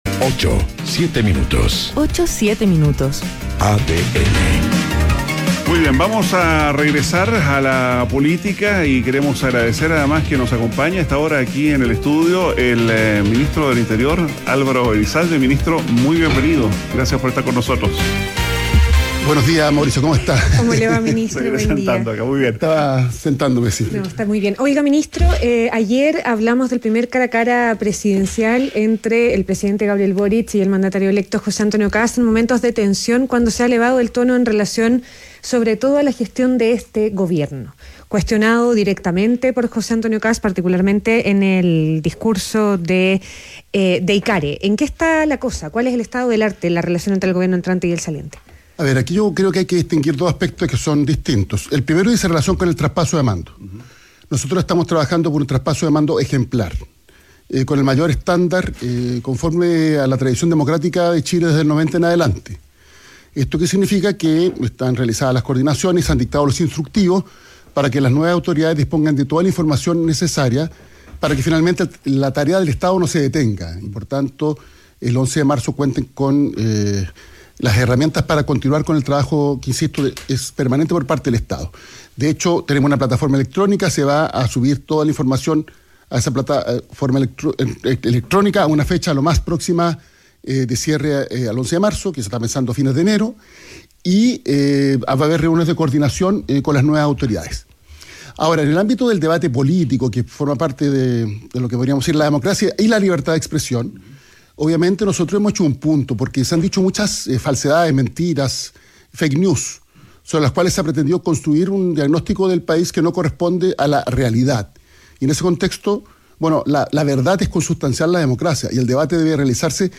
ADN Hoy - Entrevista a Álvaro Elizalde, ministro del Interior